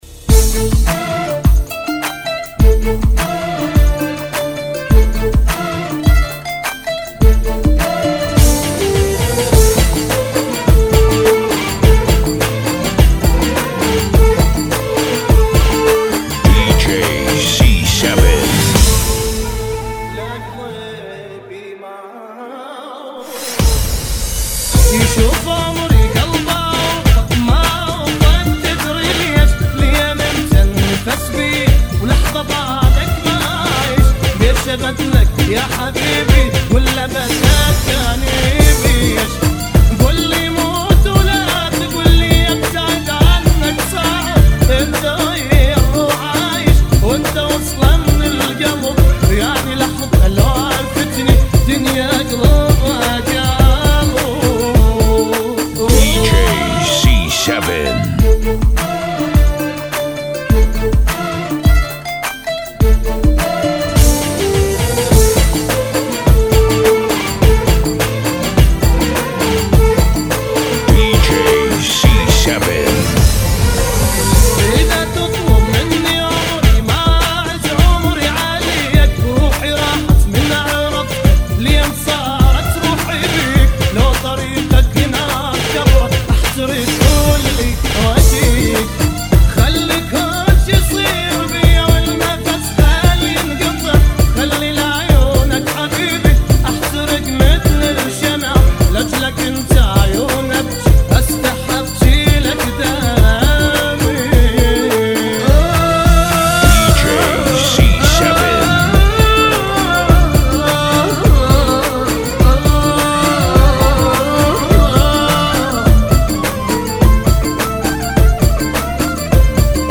BPM 104